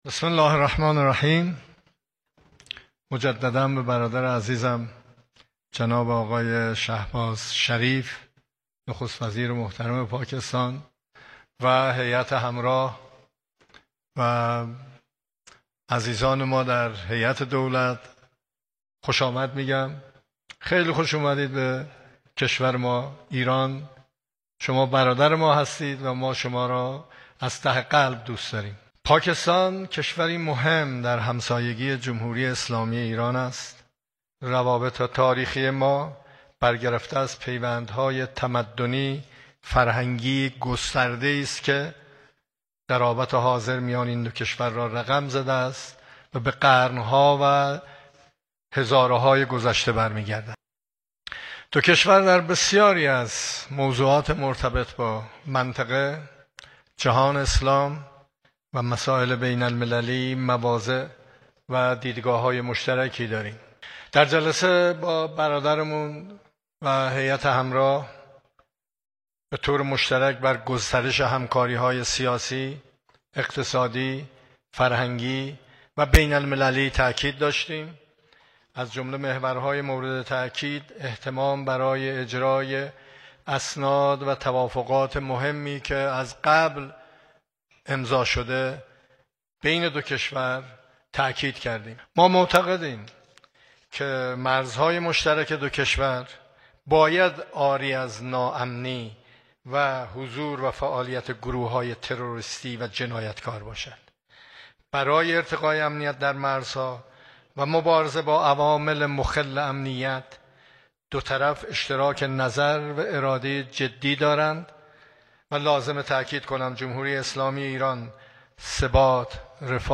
سخنان رییس جمهور در نشست خبری مشترک با نخست وزیر پاکستان
تهران- ایرنا- مسعود پزشکیان عصر امروز دوشنبه ۵ اردیبهشت ۱۴۰۴ در نشست خبری مشترک با نخست وزیر پاکستان با اشاره به اینکه حفظ امنیت پایدار و تلاش در جهت ایجاد روابط دوستانه با همسایگان را جزو منافع مشترک خود قلمداد می‌کنیم، گفت: جمهوری اسلامی ایران از برقراری آتش بس و ایجاد صلح بین پاکستان و هند استقبال می‌کند.